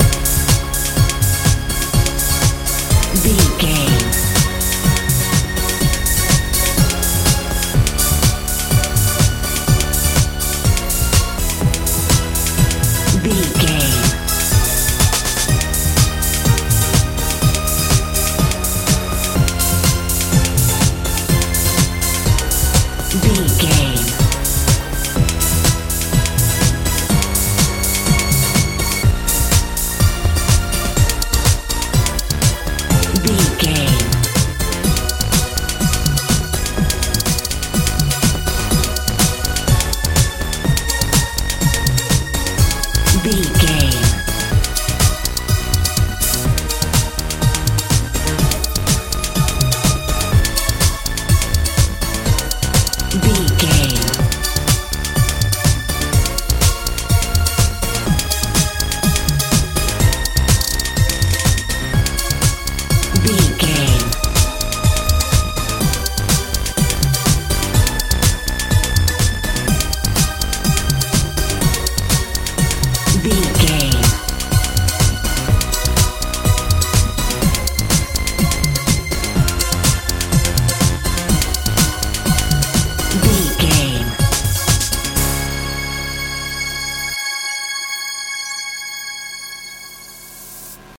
techno
Ionian/Major
C♯
fun
playful
synthesiser
bass guitar
drums
uplifting
futuristic
bouncy